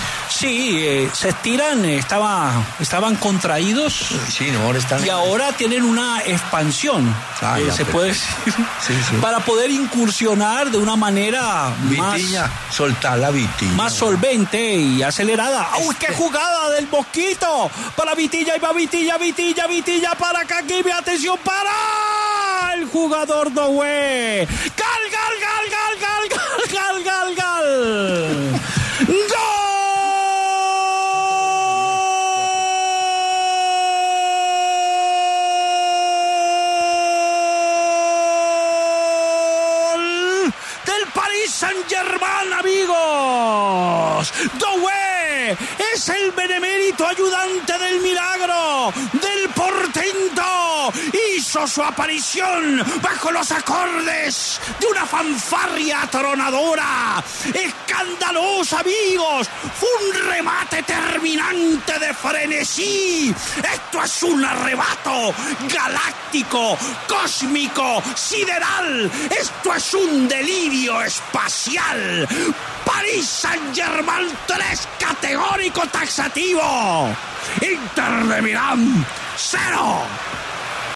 Martín De Francisco ‘enloqueció’ con el tercer gol del PSG, una anotación de Doué.
En su narración, Martín De Francisco, calificó la goleada del PSG como “escandalosa”.
“Un remate de frenesí. Es un arrebato galáctico, cósmico, sideral, un delirio espacial”, gritó con ímpetu De Francisco.